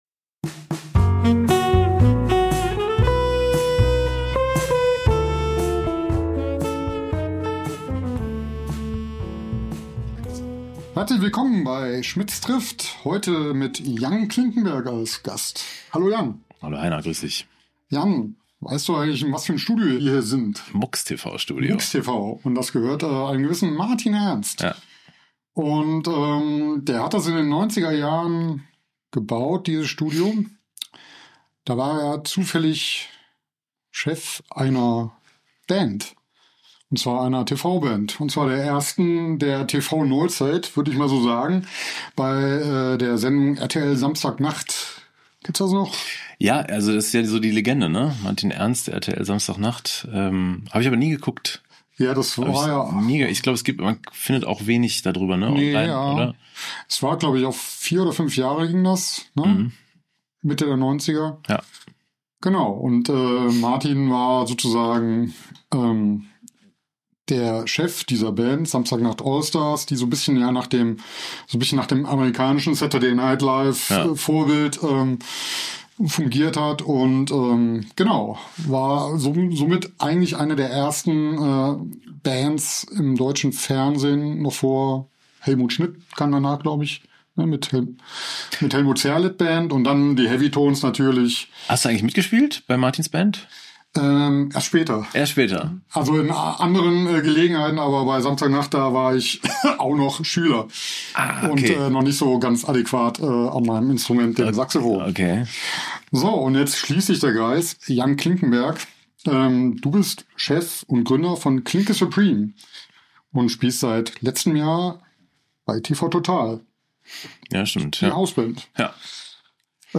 Wir sprechen über den Alltag im TV-Studio und welche Herausforderungen dieser speziell an die Musiker stellt. Vor dem Hintergrund seiner Jazz-Ausbildung erläutert er dabei auch ausführlicher seine Band-Philosophie.